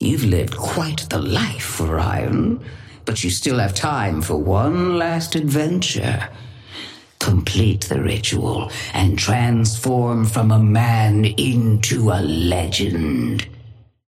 Sapphire Flame voice line - You've lived quite the life, Orion, but you still have time for one last adventure.
Patron_female_ally_orion_start_01.mp3